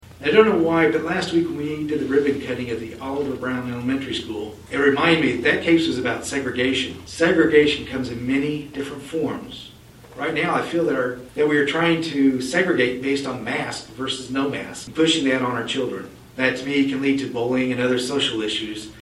The board held a special meeting Wednesday night to adopt the plan before the start of classes.